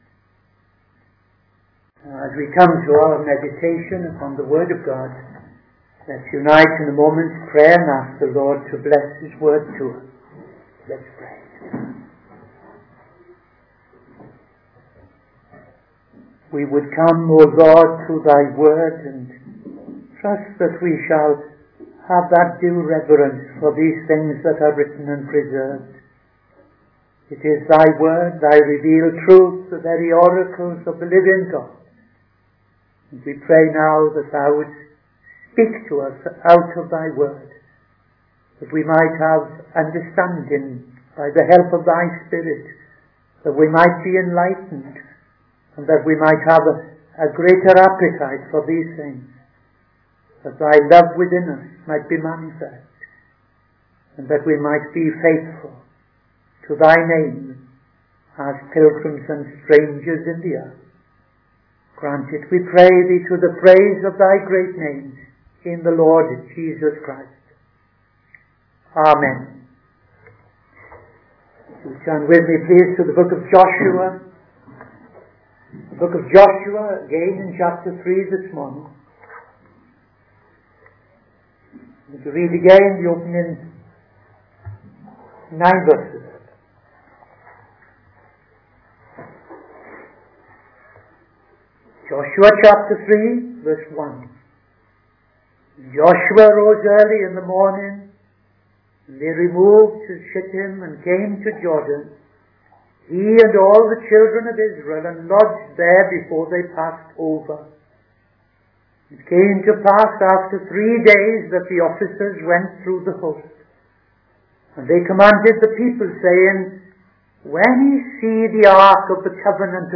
Midday Sermon - TFCChurch
Midday Sermon 16th November 2025